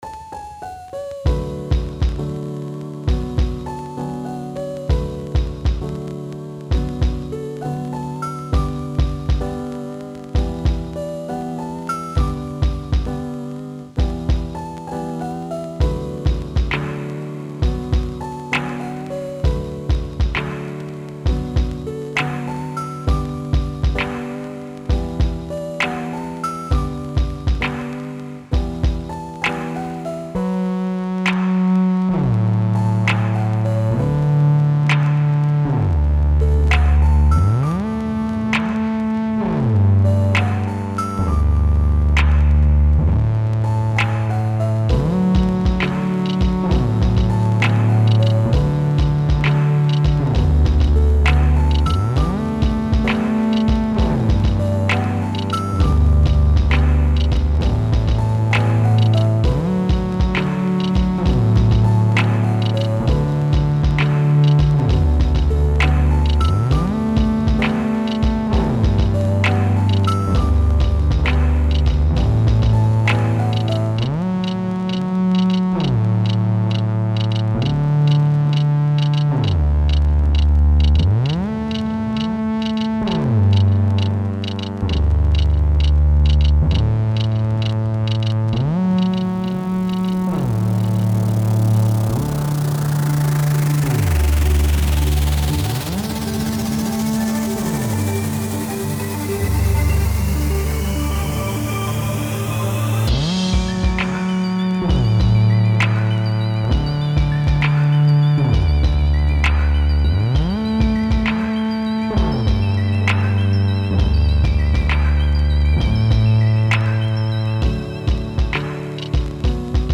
Warm beats and peaceful melodies create a cosy atmosphere.
Style Style EDM/Electronic, Hip-Hop
Mood Mood Calming, Relaxed
Featured Featured Drums, Piano, Synth
BPM BPM 66